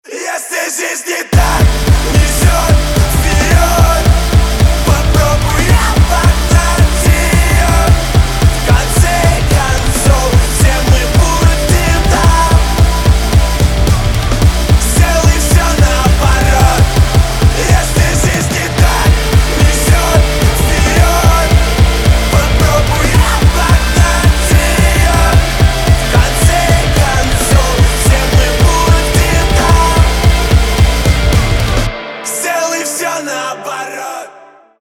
громкие
жесткие
мощные
Alternative Rock
мрачные